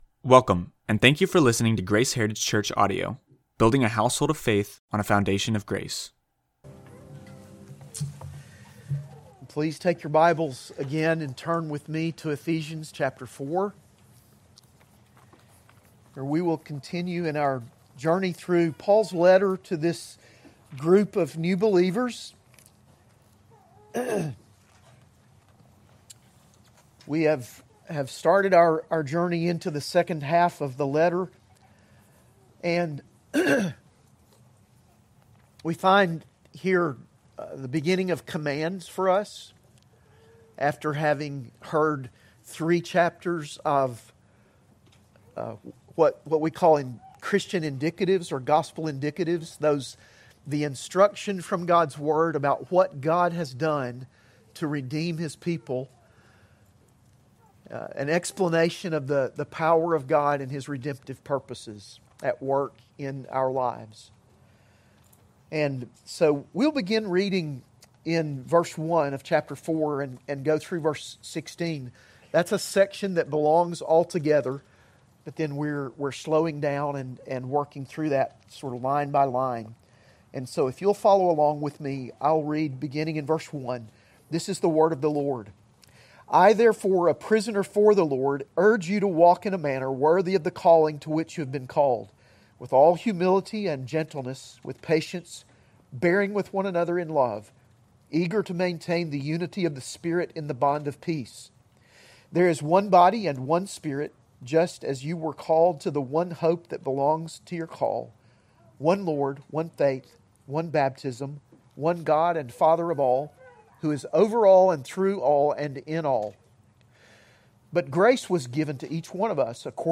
AM Worship Sermon